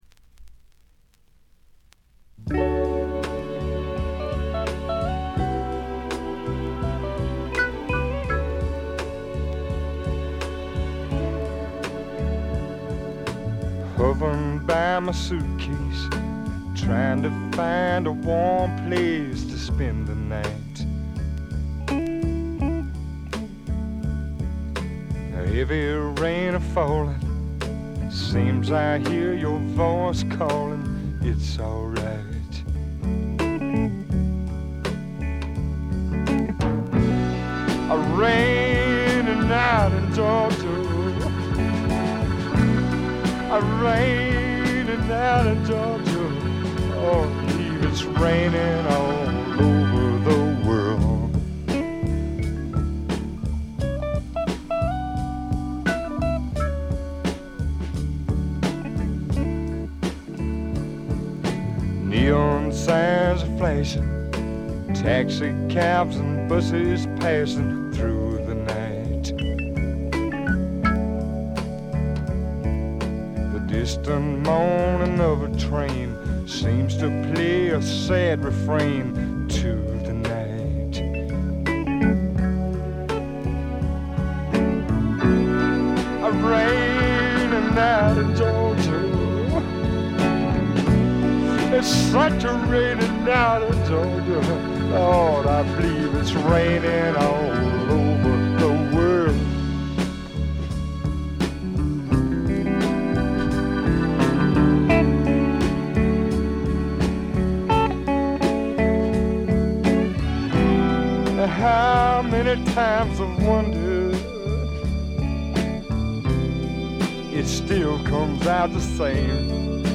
軽微なバックグラウンドノイズ、散発的なプツ音が数か所。
甘酸っぱい名曲
試聴曲は現品からの取り込み音源です。
guitar, harmonica